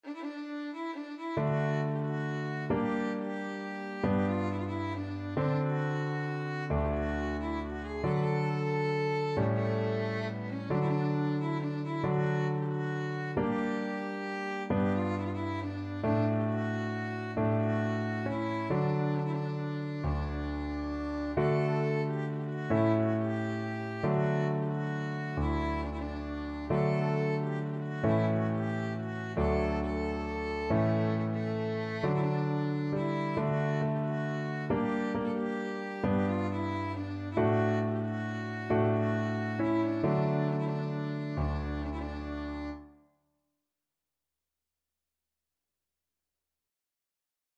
Violin
D major (Sounding Pitch) (View more D major Music for Violin )
3/4 (View more 3/4 Music)
One in a bar .=45
World (View more World Violin Music)
Romanian